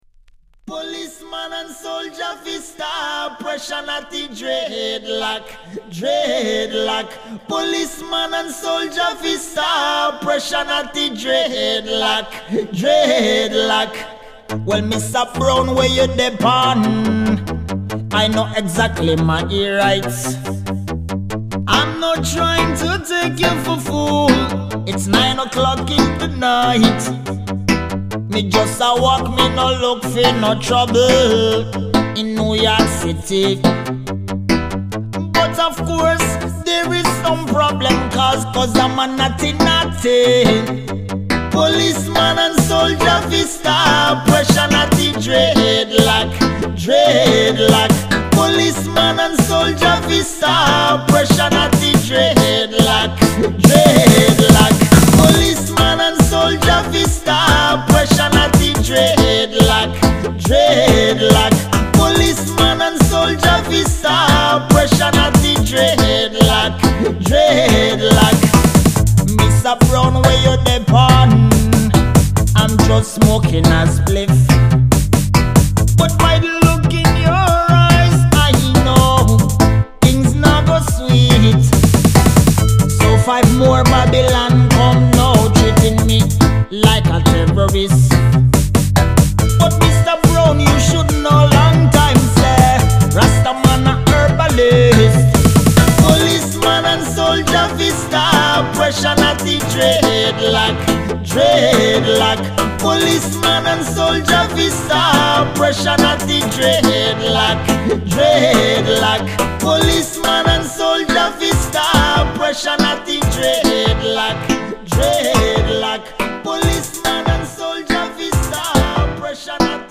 Accapella